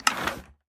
Minecraft Version Minecraft Version latest Latest Release | Latest Snapshot latest / assets / minecraft / sounds / ui / loom / select_pattern1.ogg Compare With Compare With Latest Release | Latest Snapshot